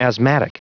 Prononciation du mot asthmatic en anglais (fichier audio)
Prononciation du mot : asthmatic
asthmatic.wav